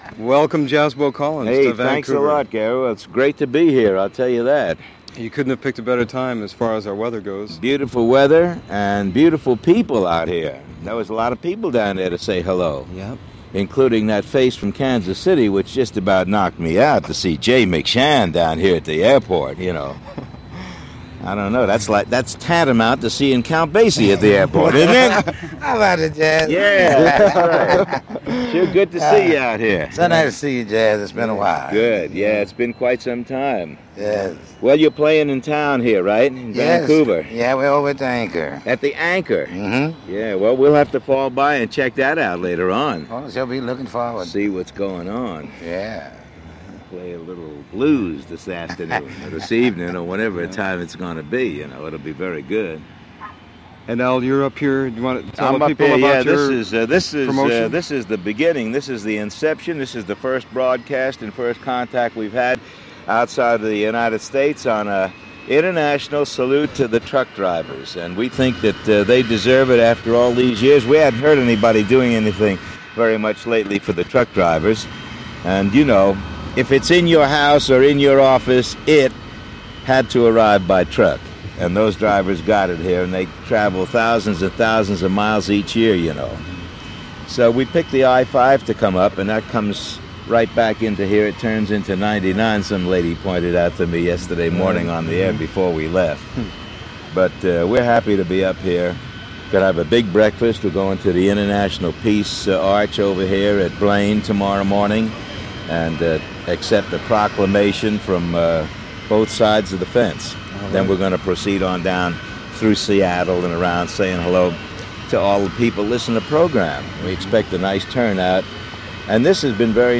Interviews | Project Jazz Verbatim